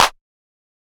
Clap 3.wav